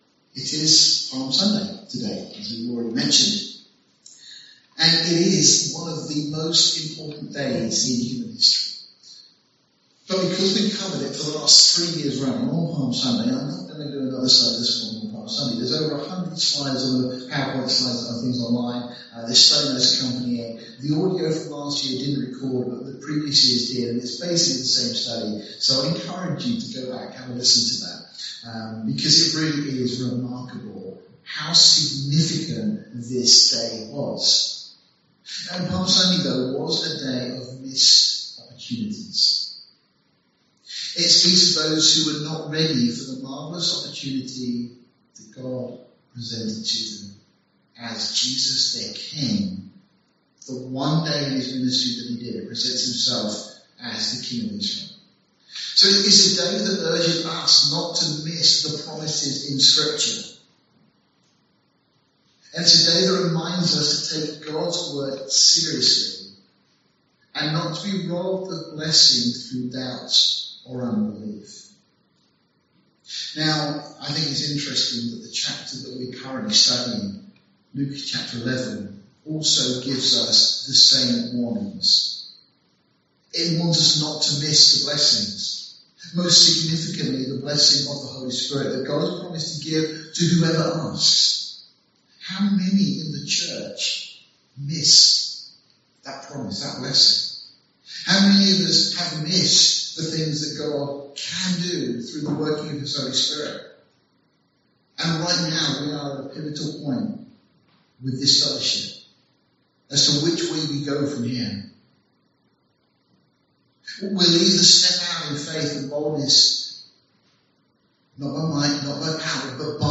**unfortunalty the audio quailty of this teaching is very poor due to a technical issue at the time of recording**